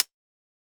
UHH_ElectroHatC_Hit-10.wav